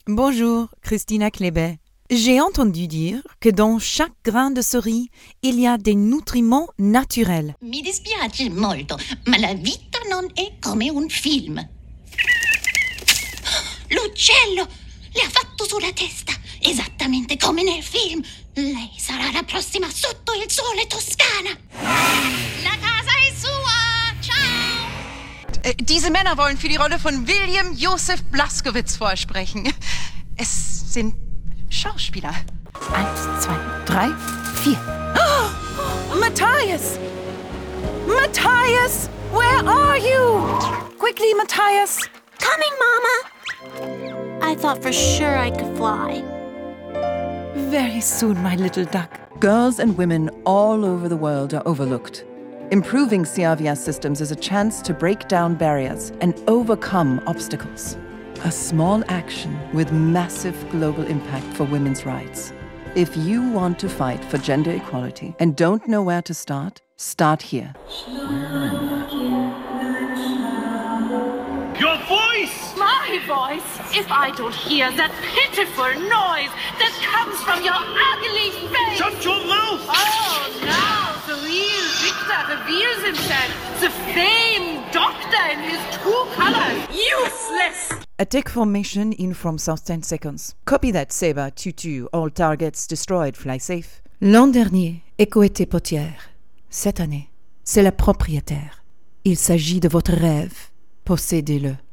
PROFESSIONAL HOME STUDIO:
Neumann TLM 103
The Sophisticated, International, Clear and Trustworthy Voice You’re Looking For